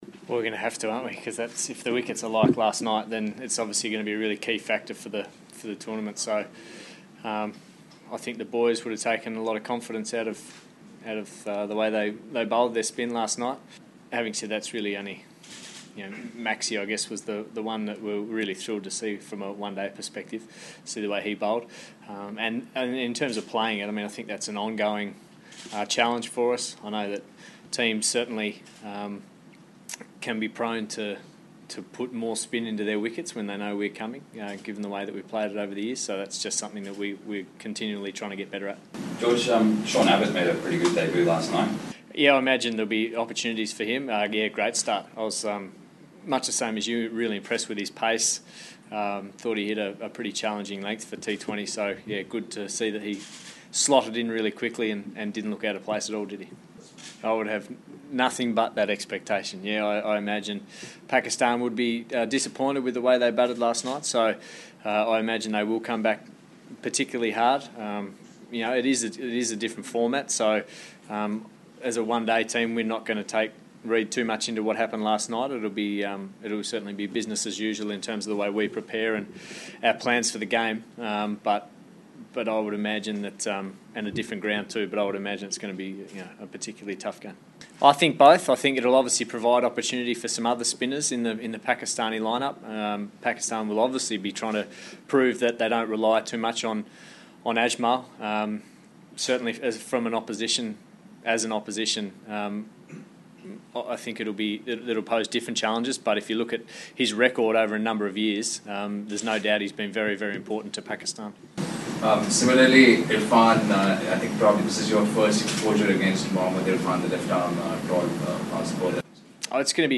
George Bailey, the Australia ODI captain, pre-ODI series media conference, 6 October